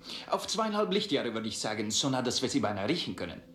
Elmar Wepper beantwortet als Pavel Andreievich Chekov Kirk's Frage, wie nahe man dem klingonischen Außenposten bei gegenwärtigem Kurs und Geschwindigkeit kommen würde, mit einem kleinen Witzchen.